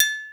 Perc_111.wav